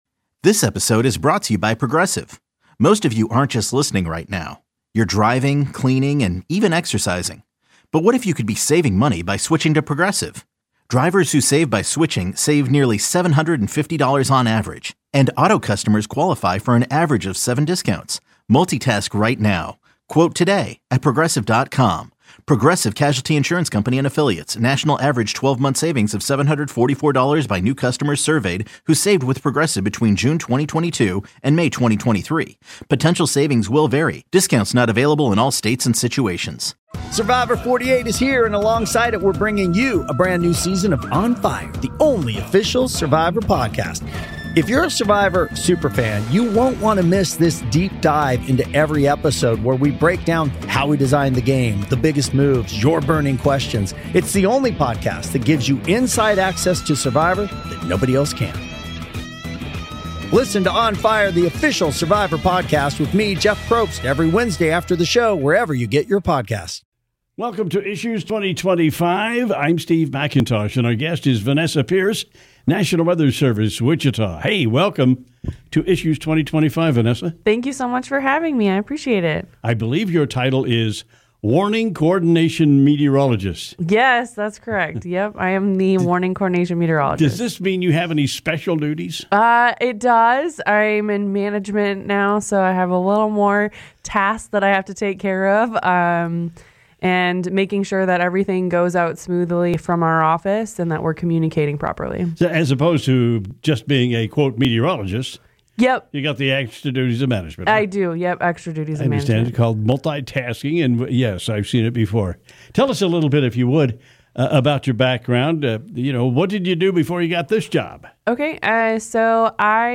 Listen to in-depth discussions with local leaders about issues in the Wichita community.